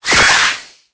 Cri_0818_EB.ogg